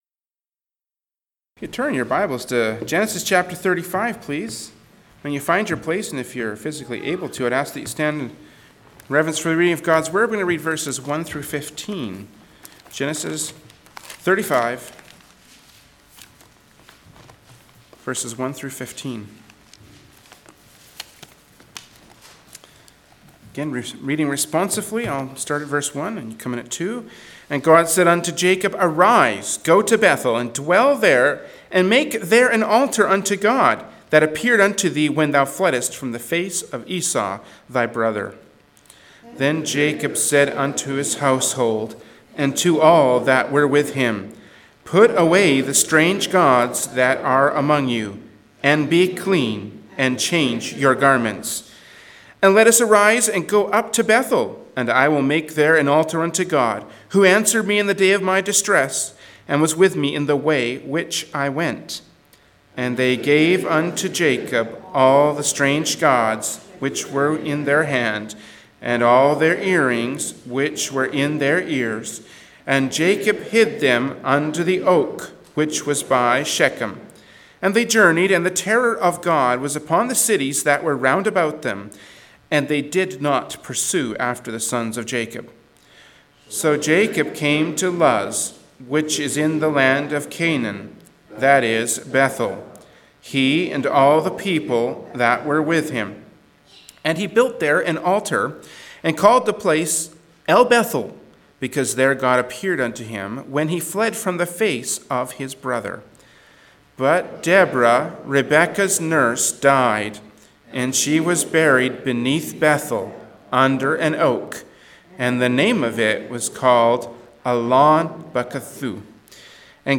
Genre: Preaching.
Passage: Genesis 35:1-15 Service Type: Sunday Morning Worship Service “Back to Bethel” from Sunday Morning Worship Service by Berean Baptist Church.